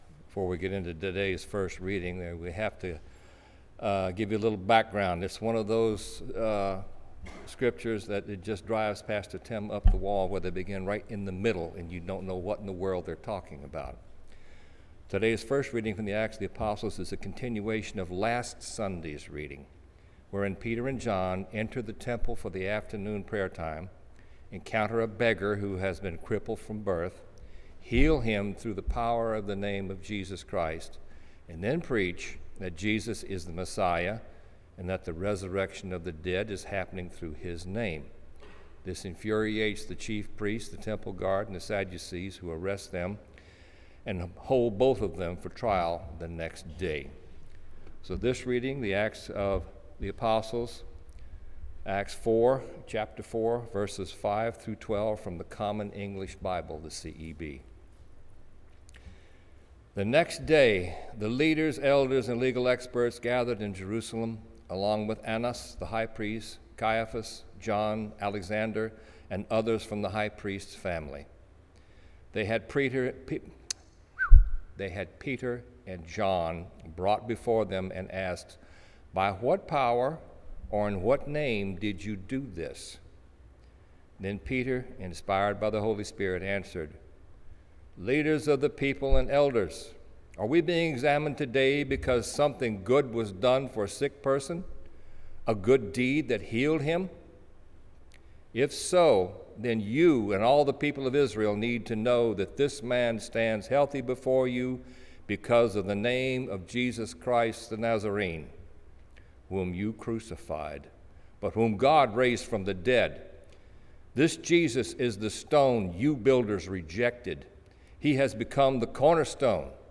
St. Charles United Methodist Church Sermons